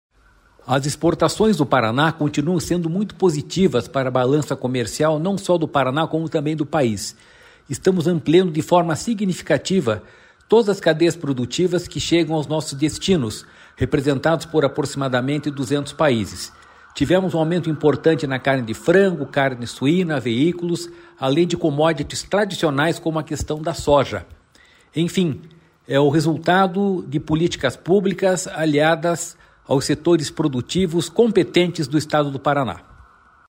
Sonora do diretor-presidente do Ipardes, Jorge Callado, sobre as exportações do Paraná no 1º trimestre de 2025